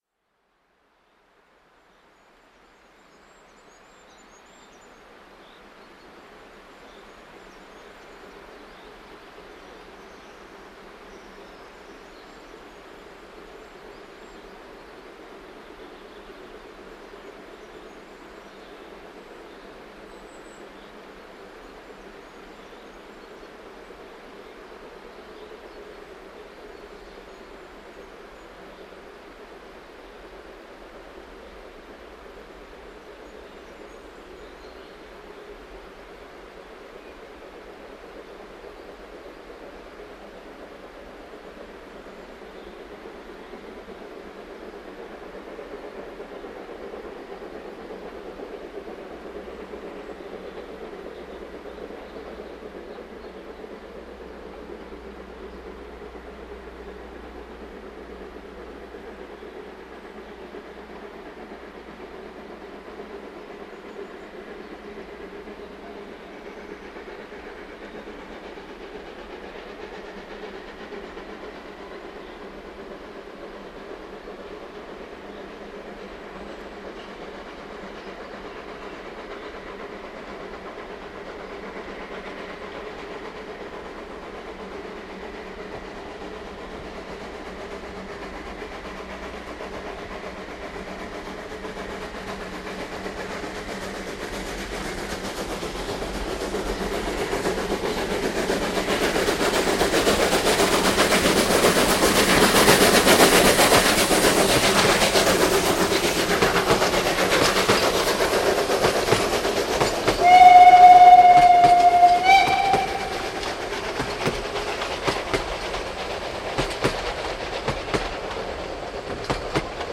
99 1542  mit Zug 25 im Tal zwischen Schmalzgrube und Schlössel, um 14:35h am 17.05.2007.   Hier anhören: